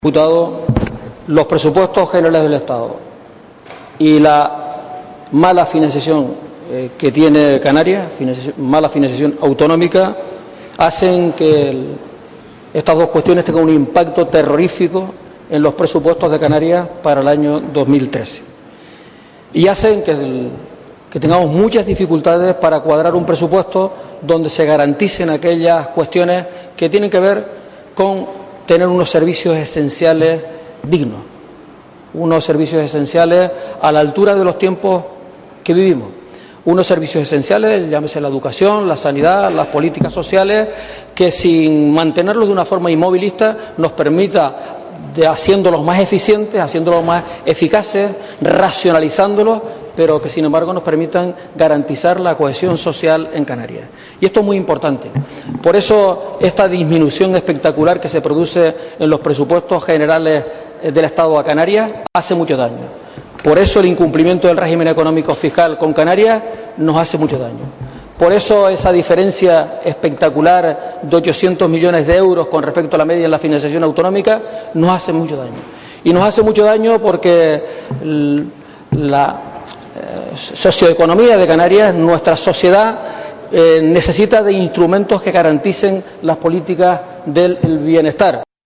Habrá muchas dificultades "para garantizar unos servicios esenciales dignos" en sanidad, educación y políticas sociales, dijo Rivero en una respuesta en el pleno del Parlamento al diputado nacionalista José Miguel Barragán.